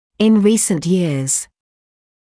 [ɪn ‘riːsnt jɪəz][ин ‘риːснт йиэз]в последние годы